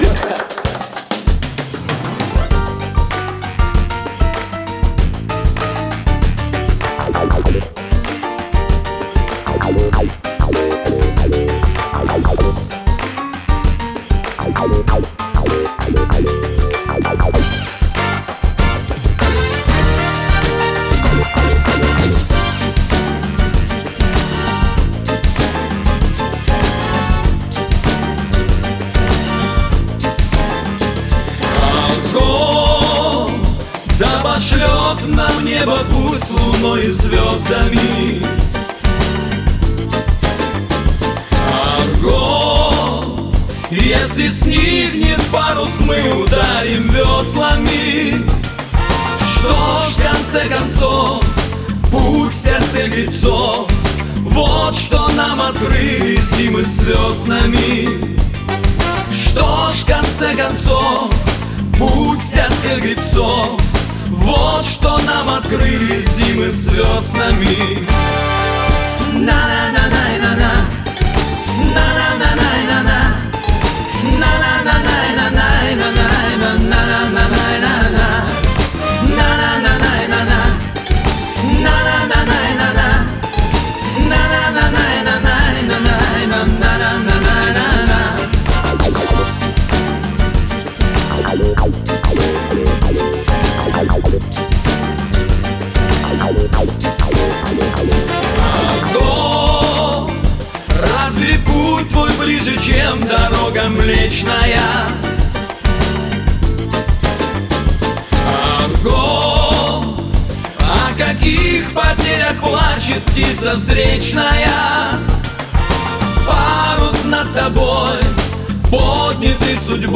вокал, гитара
вокал, бас-гитара, клавишные
альт-саксофон, клавишные
ударные, перкуссия